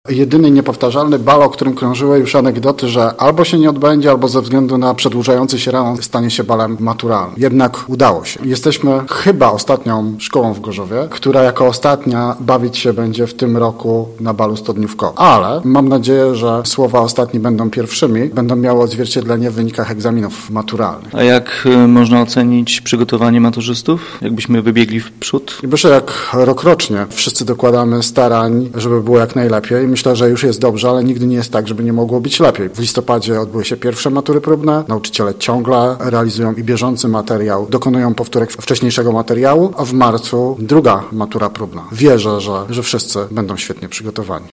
Osiemdziesiąt dni przed matura, blisko dwustu uczniów i nauczycieli z pierwszego Liceum Ogólnokształcącego w Gorzowie, bawiło się na tradycyjnej Studniówce.